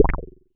Wet_Bass_F1.wav